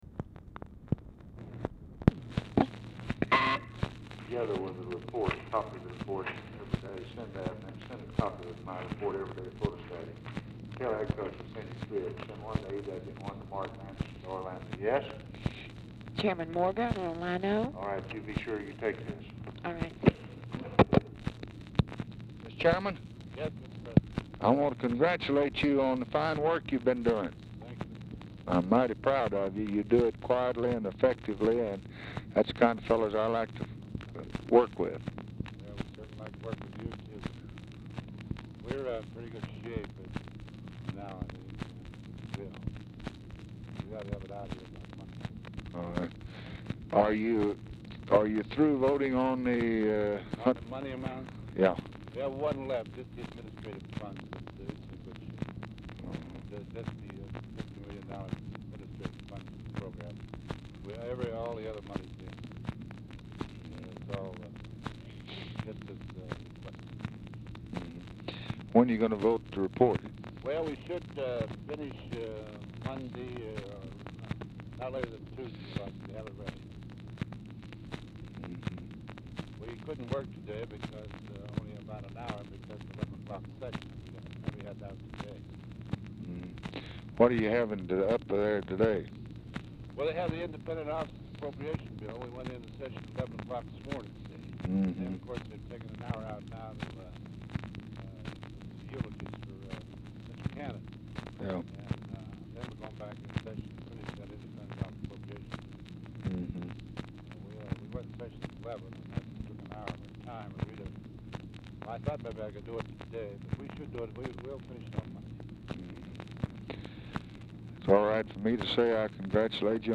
OFFICE CONVERSATION PRECEDES CALL; LBJ TELLS OFFICE SECRETARY TO "TAKE THIS"; MORGAN'S VOICE IS FAINT
Format Dictation belt
Specific Item Type Telephone conversation Subject Congressional Relations Foreign Aid Legislation White House Administration